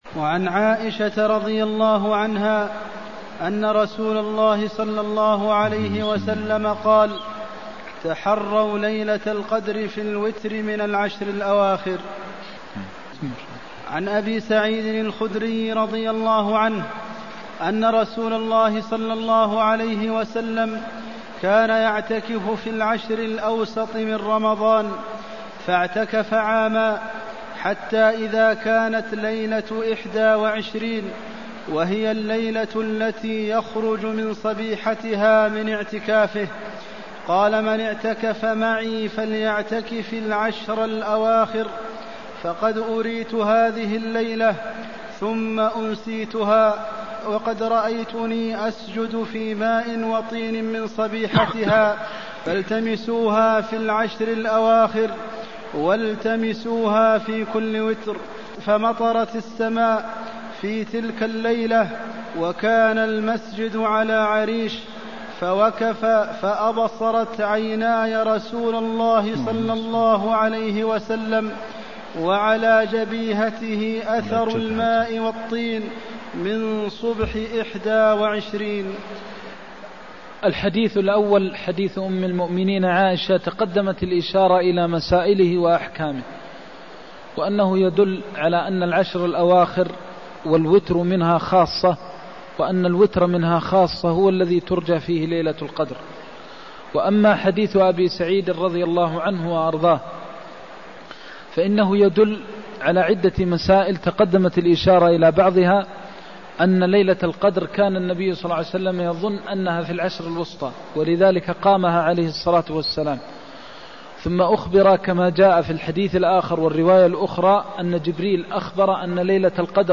المكان: المسجد النبوي الشيخ: فضيلة الشيخ د. محمد بن محمد المختار فضيلة الشيخ د. محمد بن محمد المختار التماس ليلة القدر في الوتر من العشر الأواخر (197)